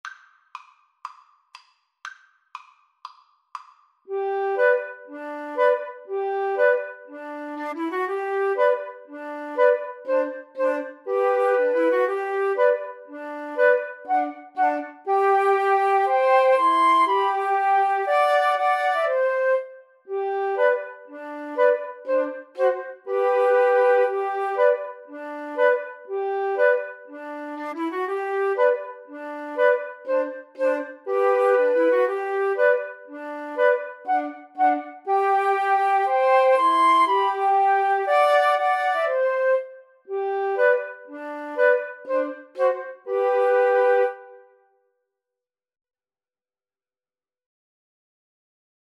G major (Sounding Pitch) (View more G major Music for Flute Trio )
Moderately Fast
Traditional (View more Traditional Flute Trio Music)